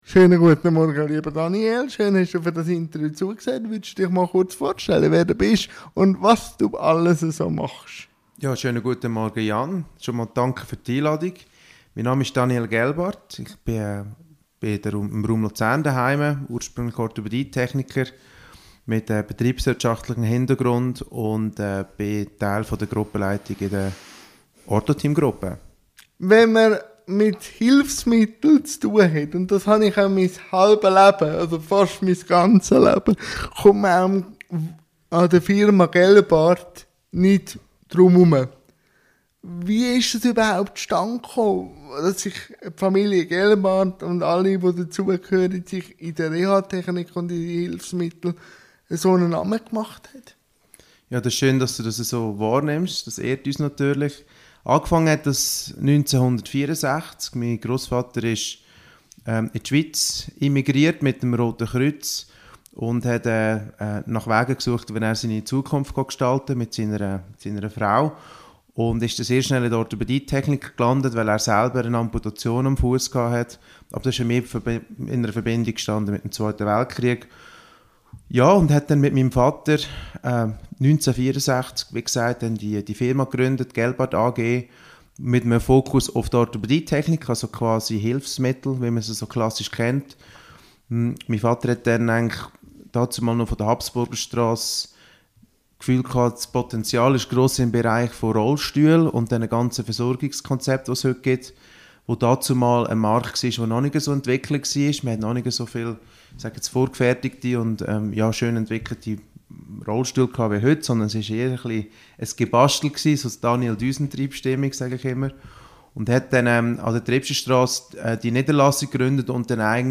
INTERVIEW-THEMEN